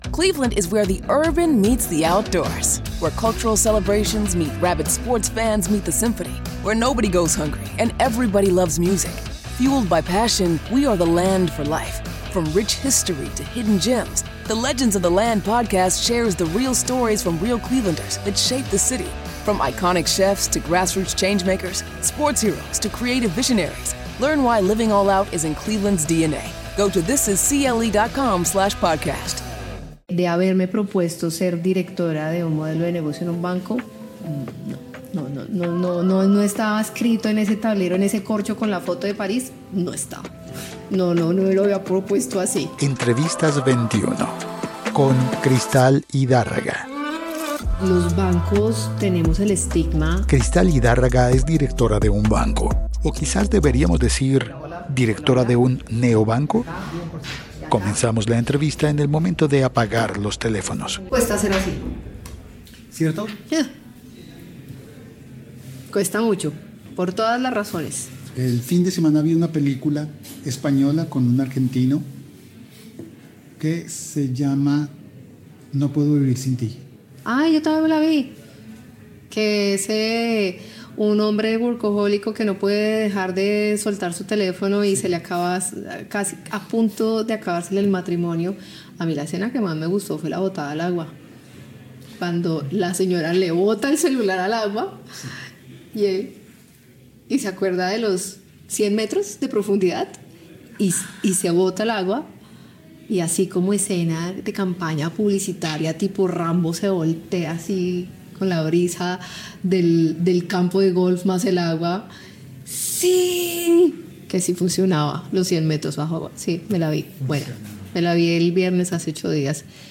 Entrevistas del Archivo